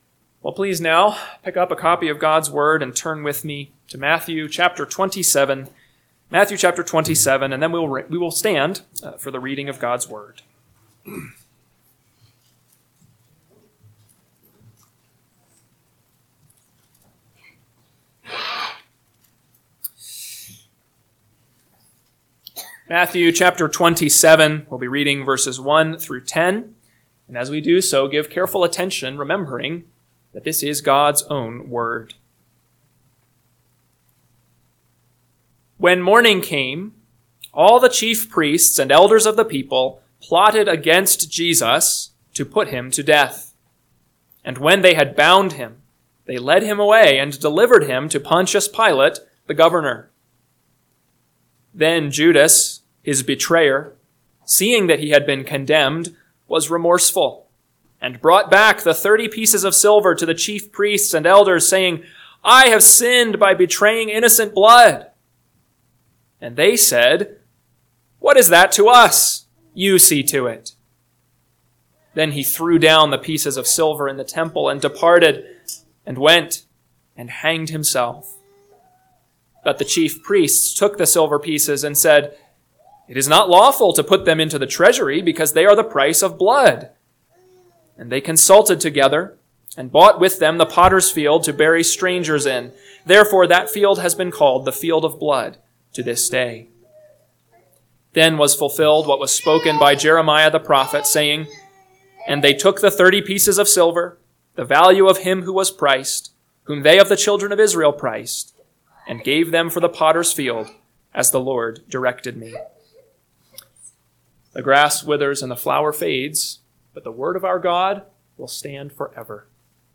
AM Sermon – 4/6/2025 – Matthew 27:1-10 – Northwoods Sermons